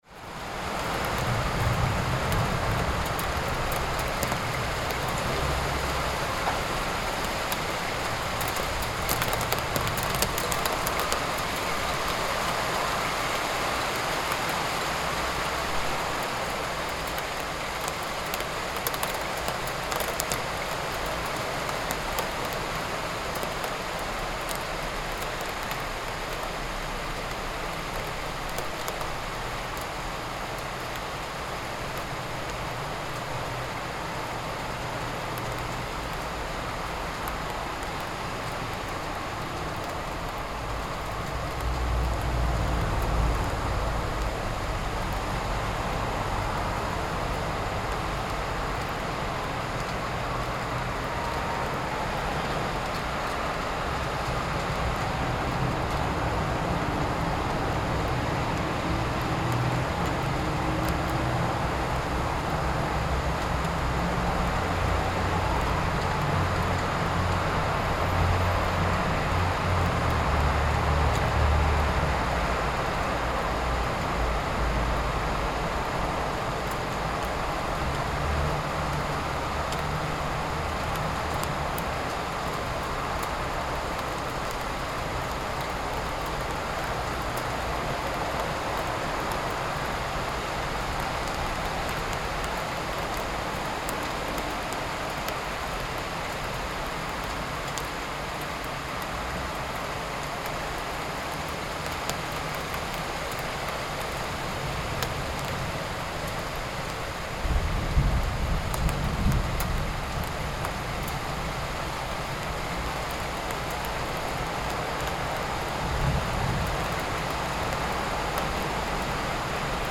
Rain Falling In Urban Area – City Ambience Sound Effect
Heavy rain falls on a busy city street, and raindrops hit hard surfaces. Vehicles drive on wet urban asphalt, adding realistic city ambience in rain falling in urban area – city ambience sound effect. Distant thunder occasionally rumbles in the background, creating a dramatic and immersive sound effect.
Rain-falling-in-urban-area-city-ambience-sound-effect.mp3